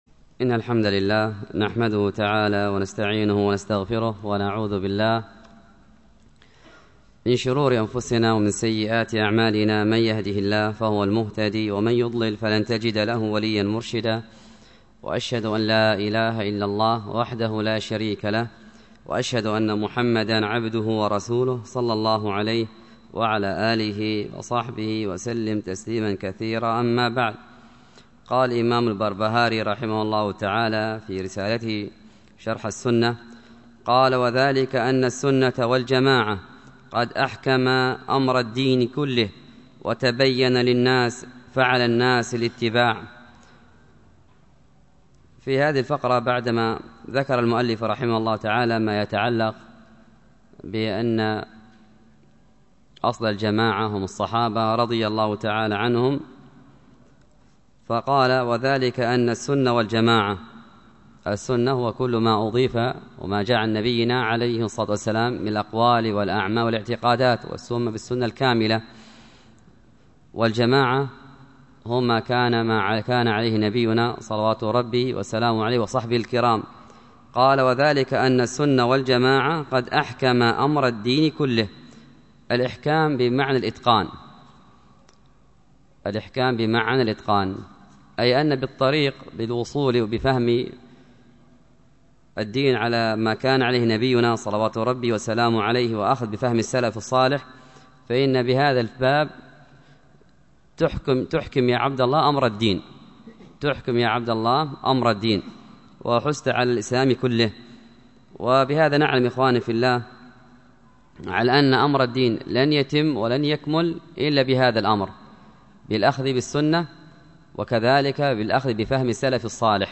المحاضرة
بمسجد الحبش بالديس الشرقية